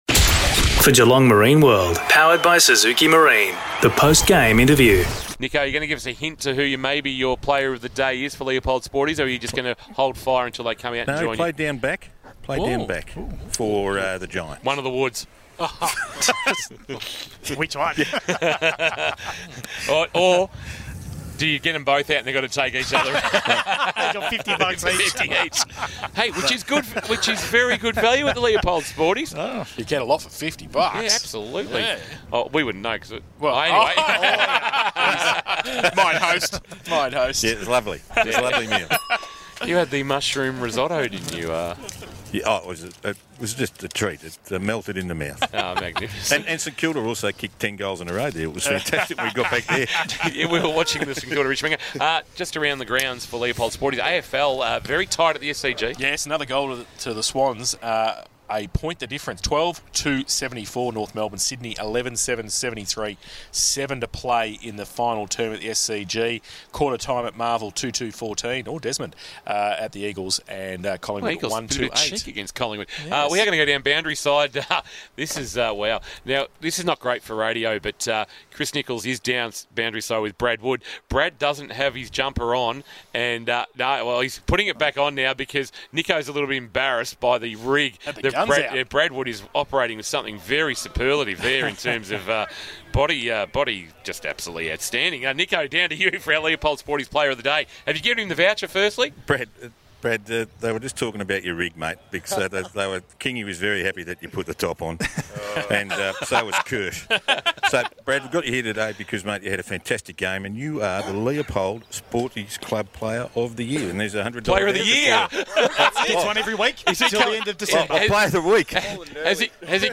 2022 - GFL ROUND 2 - GEELONG WEST vs. GROVEDALE: Post-match Interview